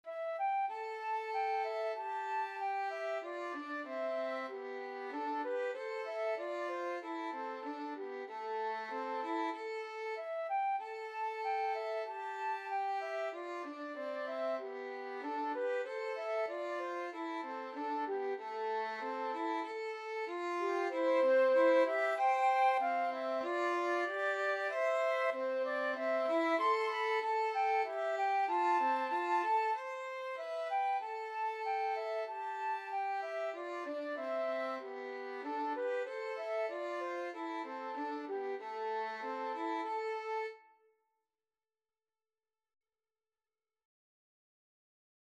Free Sheet music for Violin-Flute Duet
Traditional Music of unknown author.
C major (Sounding Pitch) (View more C major Music for Violin-Flute Duet )
= 95 Moderato
4/4 (View more 4/4 Music)
Violin-Flute Duet  (View more Easy Violin-Flute Duet Music)